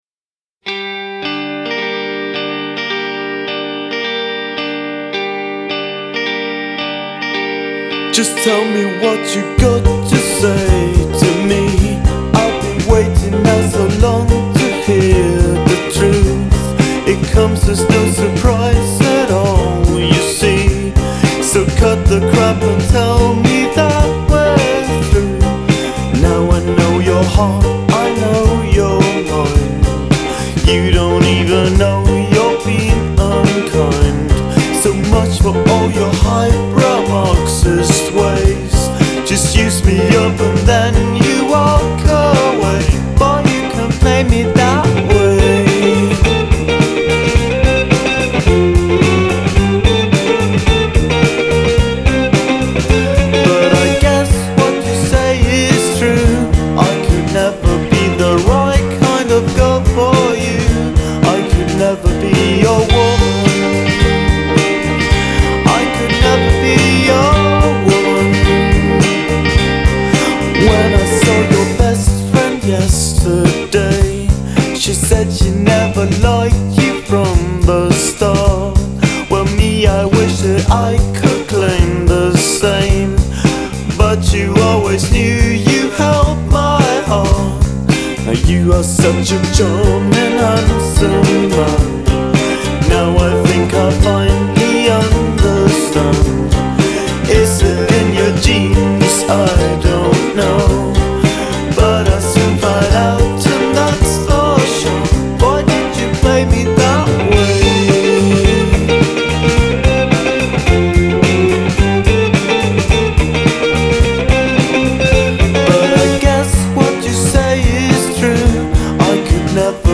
la cover